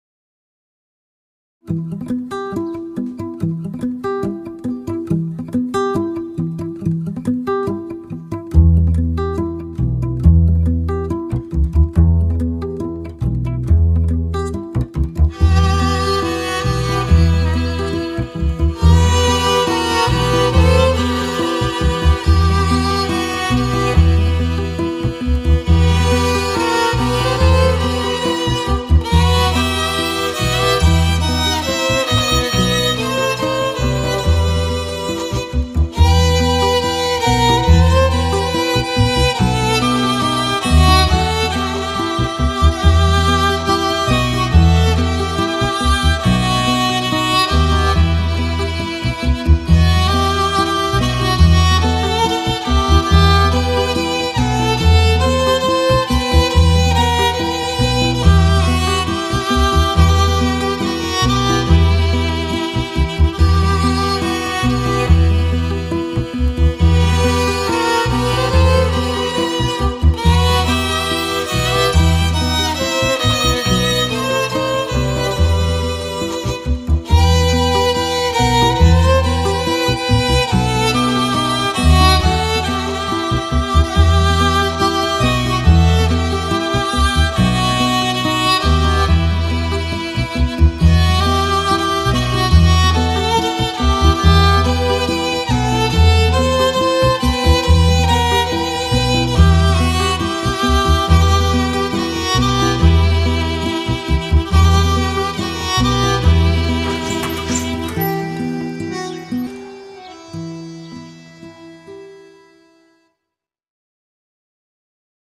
tema dizi müziği, duygusal mutlu eğlenceli fon müziği.